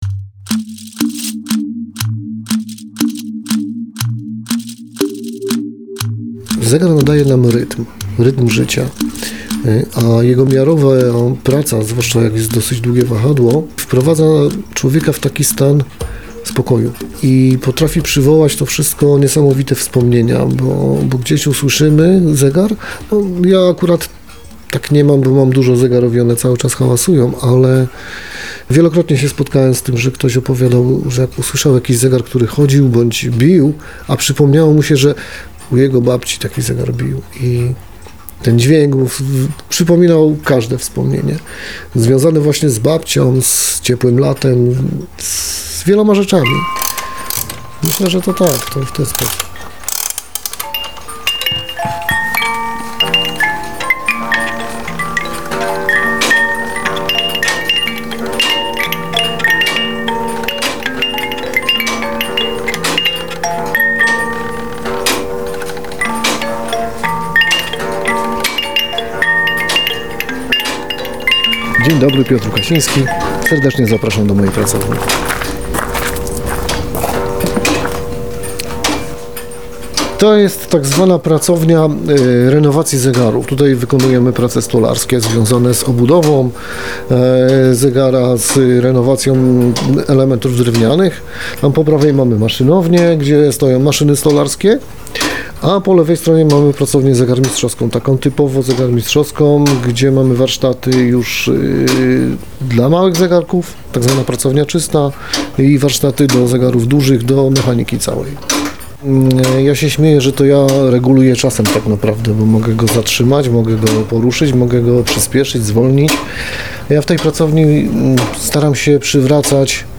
reportażu